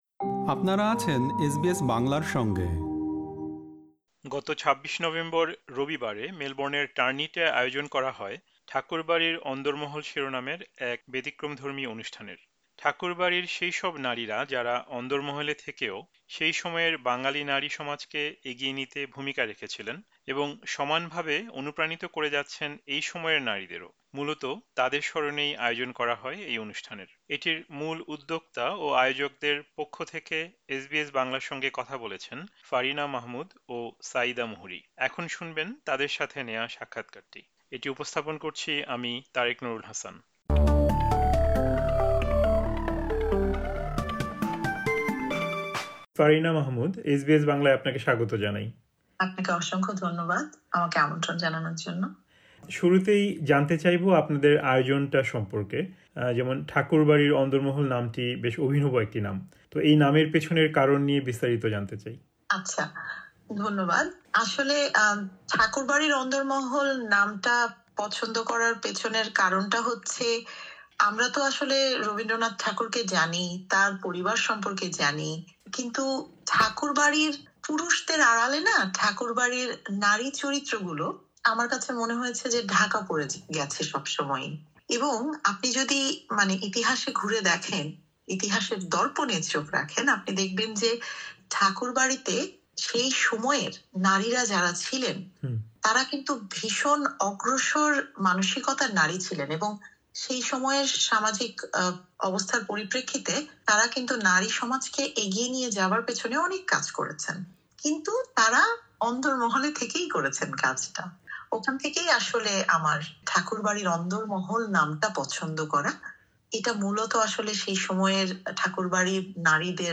গত ২৬ নভেম্বর মেলবোর্নের টার্নিটে আয়োজিত হয় ‘ঠাকুরবাড়ির অন্দরমহল’ শিরোনামের এই অনুষ্ঠানটি, যাতে অংশ নেন প্রায় ৮০ জন আমন্ত্রিত অতিথি। এই অনুষ্ঠানের আয়োজকরা কথা বলেছেন এসবিএস বাংলার সঙ্গে।